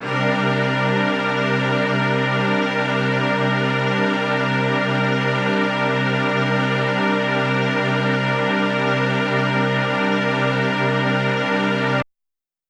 SO_KTron-Ensemble-C7:9.wav